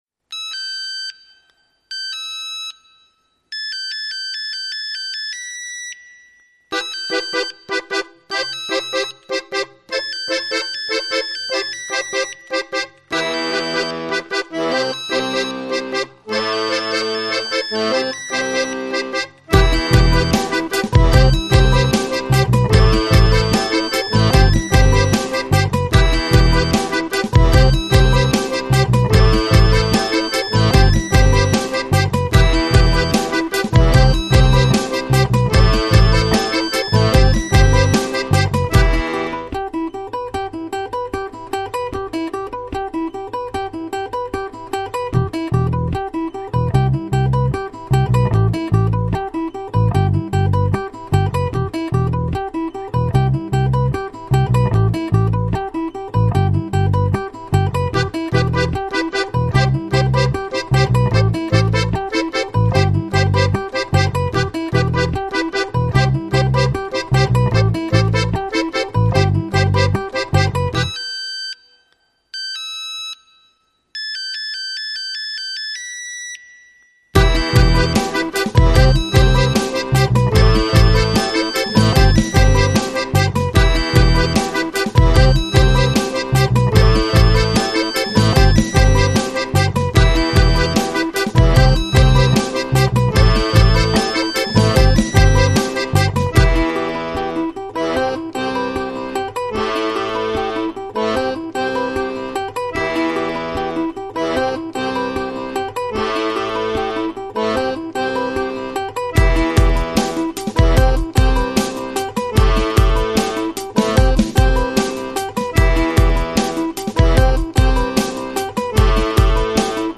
Саундтрек